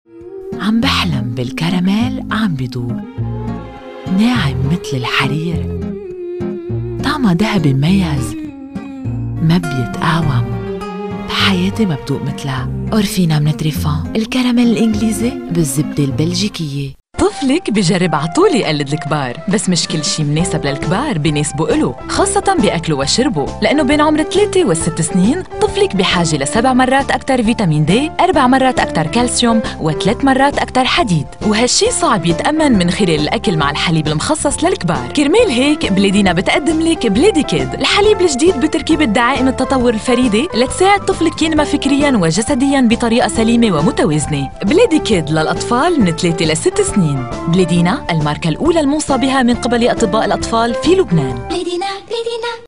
Lübnan Arapçası Seslendirme
Kadın Ses